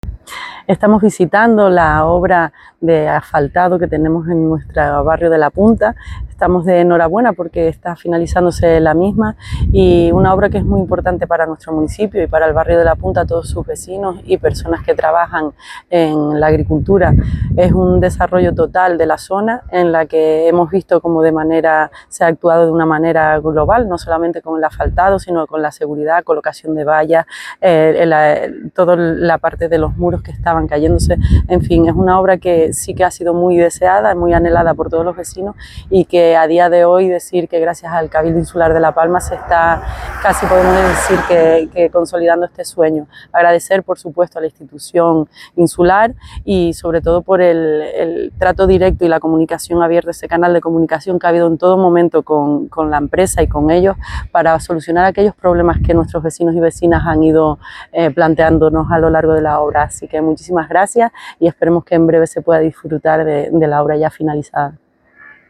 Declaraciones alcaldesa de Tijarafe.mp3